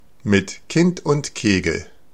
Ääntäminen
Ääntäminen US Haettu sana löytyi näillä lähdekielillä: englanti Käännös Konteksti Ääninäyte Substantiivit 1.